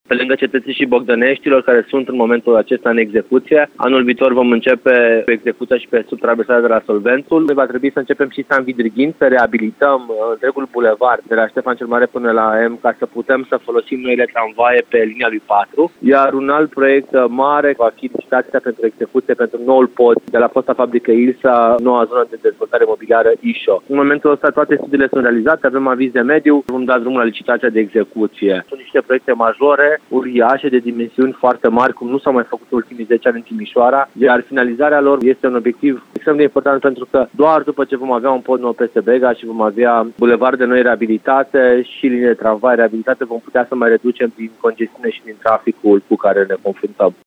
Din acest motiv, Primăria se concentrează, în acest an, pe implementarea mai multor proiecte de infrastructură, spune viceprimarul Ruben Lațcău: